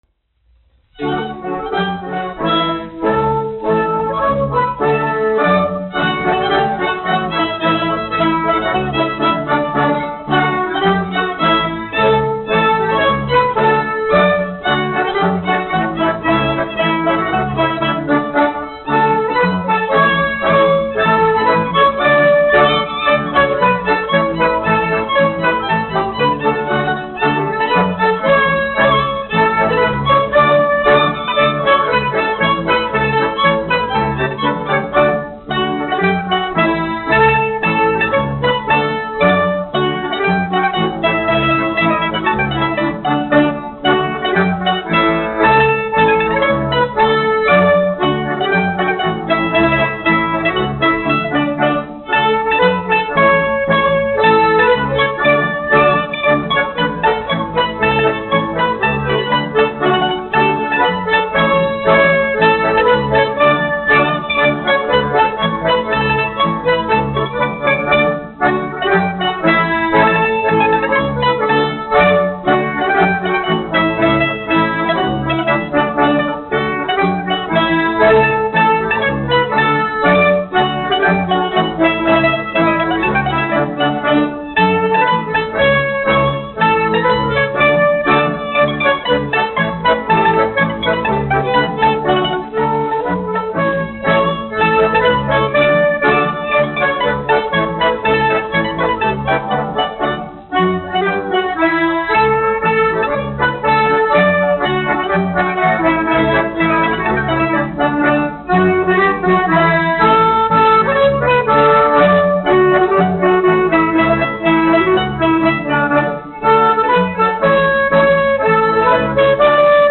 Alfrēda Vintera Jautrā kapela (mūzikas grupa), izpildītājs
1 skpl. : analogs, 78 apgr/min, mono ; 25 cm
Latviešu tautas dejas
Skaņuplate
Latvijas vēsturiskie šellaka skaņuplašu ieraksti (Kolekcija)